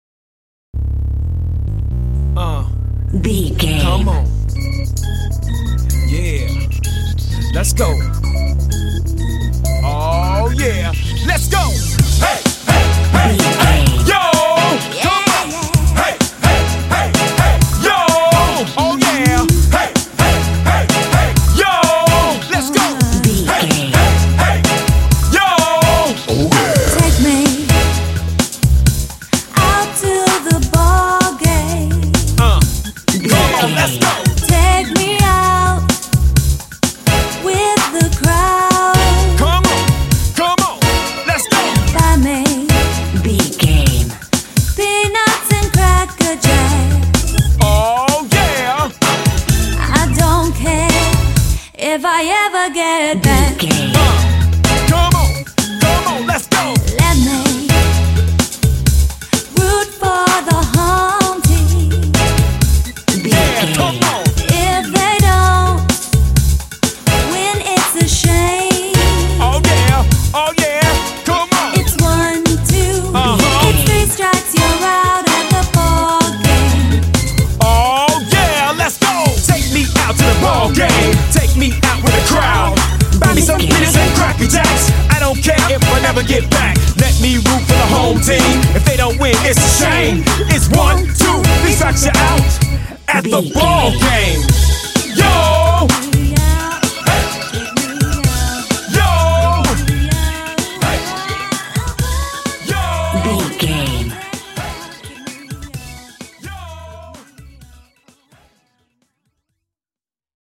Aeolian/Minor
C#
funky
happy
bouncy
groovy
electric organ
drums
bass guitar
strings
r& b